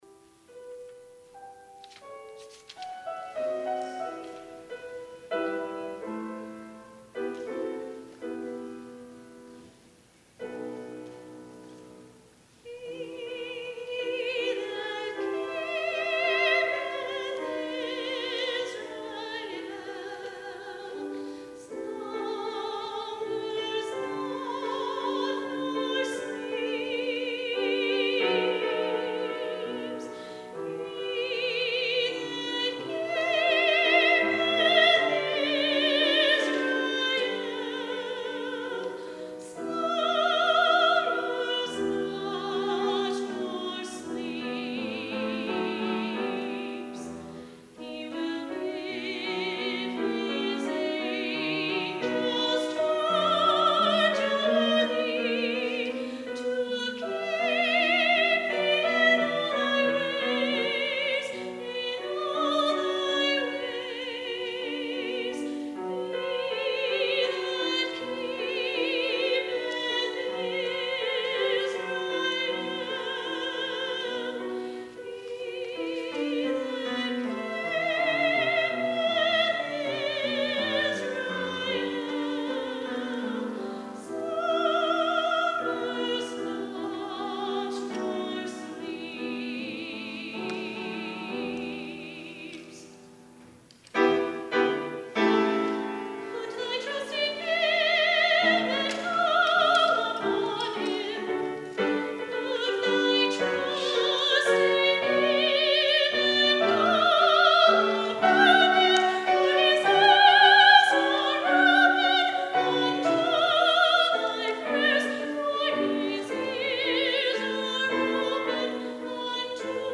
SOLO He That Keepeth Israel
soprano
piano